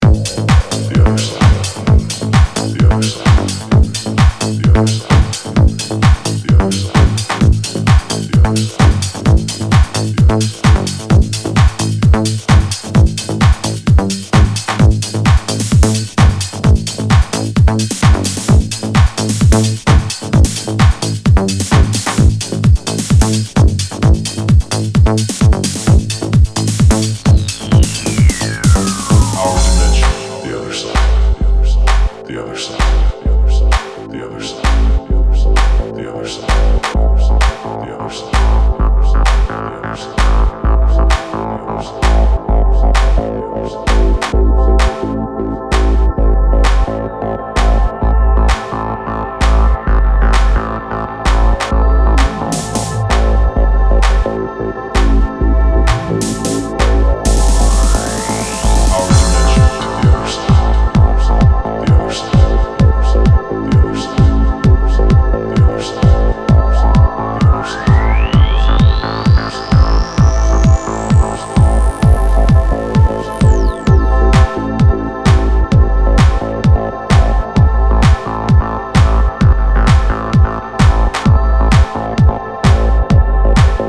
transcending yet punchy atmospheres